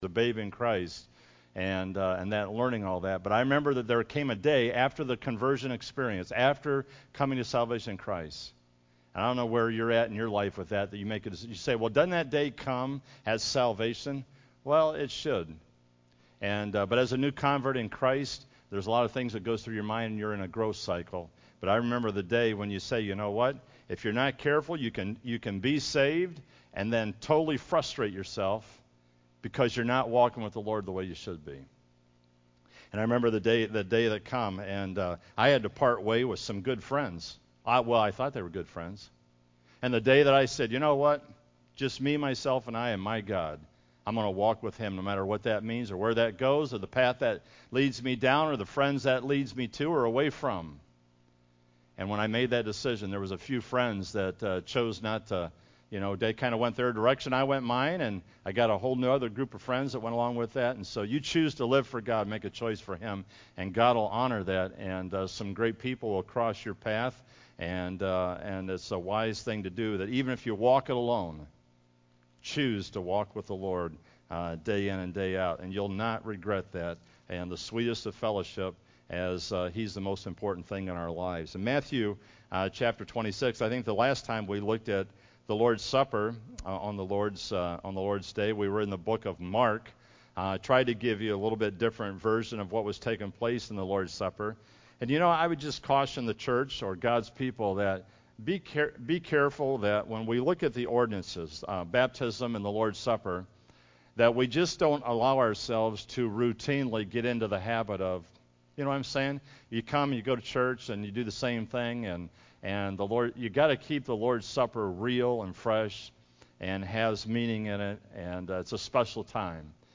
10-23-16 PM Lord’s Supper Message